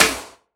SNARE 014.wav